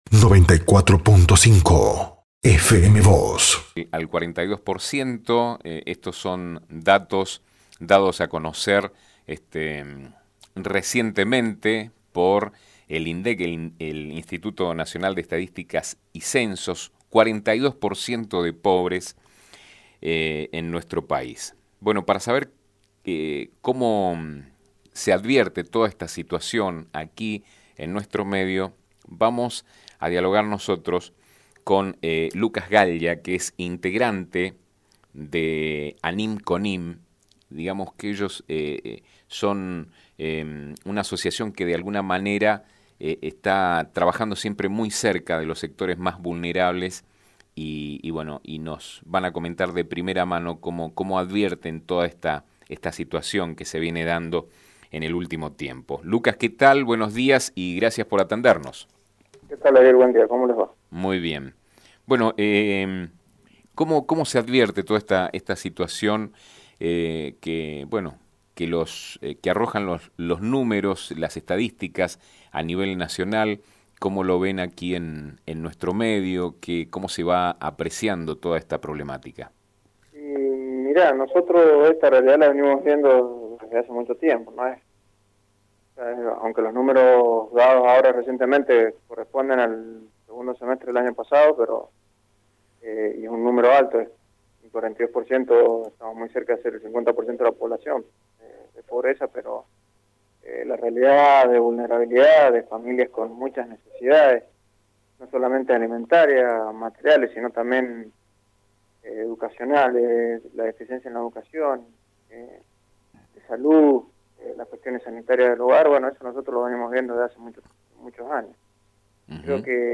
Sobre este tema y cómo se advierte la situación dio algunos detalles a FM Vos (94.5) y Diario San Rafael